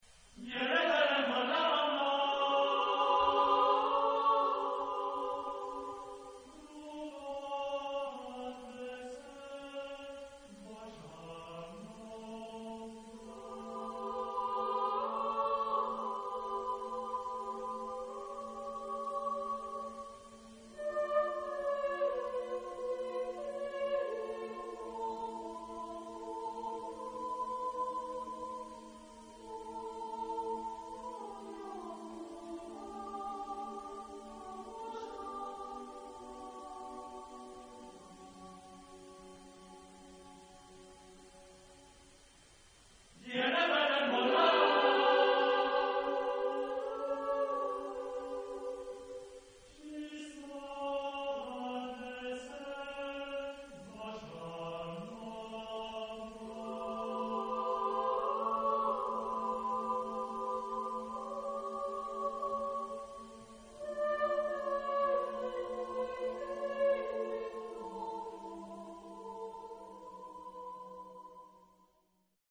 Genre-Style-Form: Secular ; Folk music ; ballad
Type of Choir: SATB (div)  (4 mixed voices )
Soloist(s): Soprano (1)  (1 soloist(s))